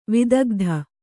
♪ vidagdha